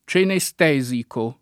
vai all'elenco alfabetico delle voci ingrandisci il carattere 100% rimpicciolisci il carattere stampa invia tramite posta elettronica codividi su Facebook cenestesico [ © ene S t $@ iko ] o cenestetico [ © ene S t $ tiko ] agg.